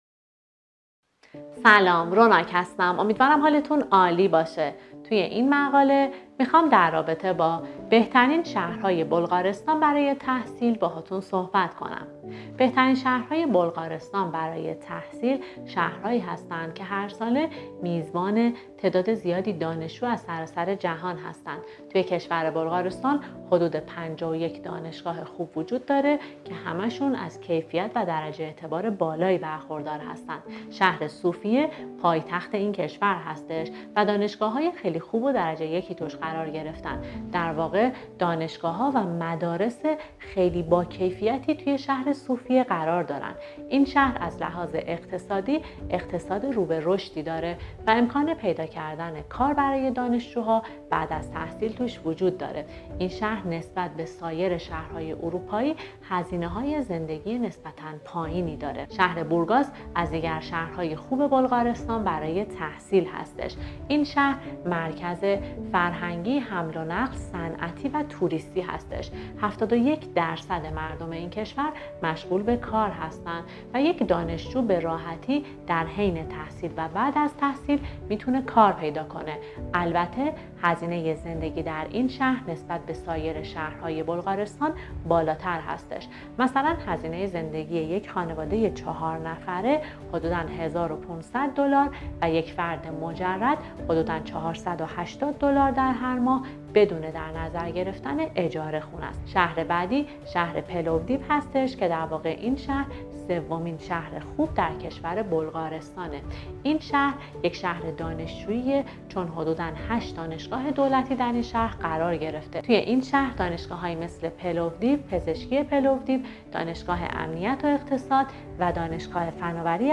پادکست بهترین شهرهای بلغارستان برای تحصیل